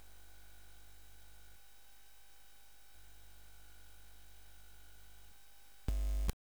BUZZ      -R.wav